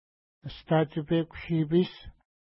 ID: 516 Longitude: -60.6215 Latitude: 53.3397 Pronunciation: əsta:tʃəpeku-ʃi:pi:s Translation: Green Moss River (small) Feature: river Explanation: Named in reference to lake Ashtatshipeku-nipi (no 515) from which it flows.